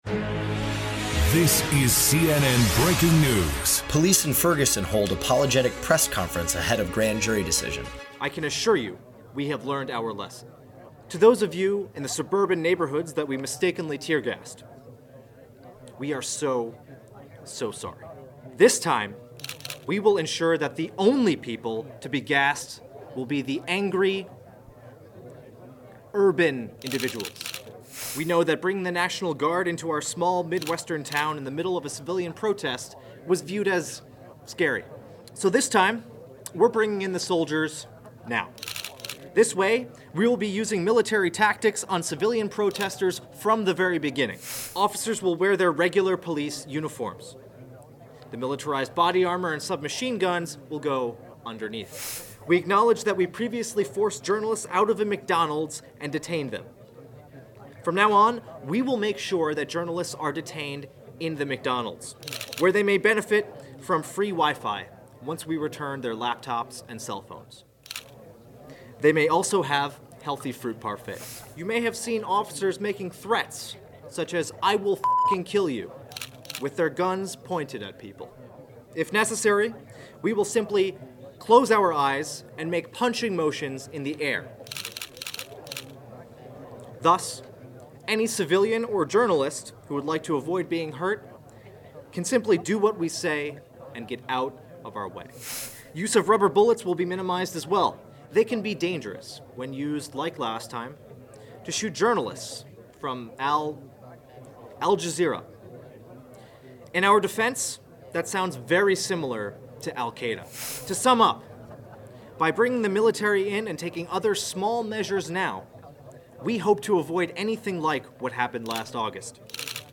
Ferguson Grand Jury Decides–Police Press Conference (Parody)